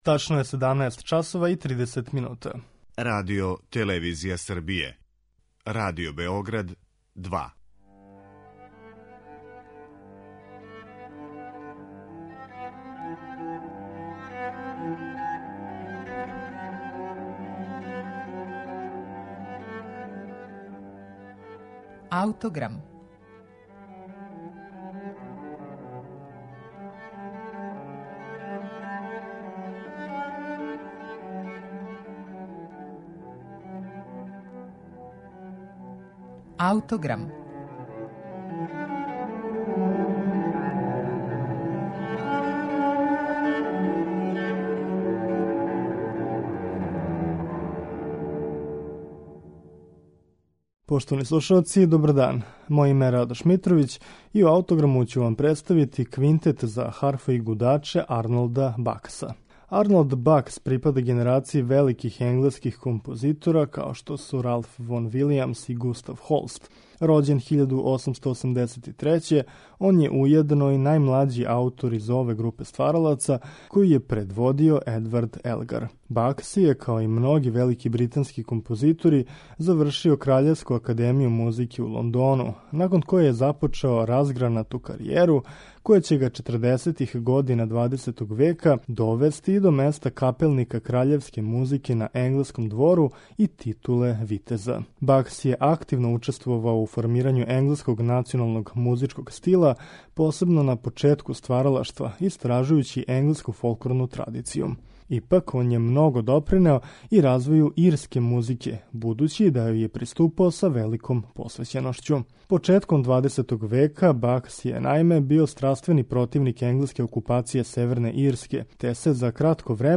Гледајући на харфу као на ирски национални инструмент, Бакс јој је посветио неколико дела.
за харфу и гудаче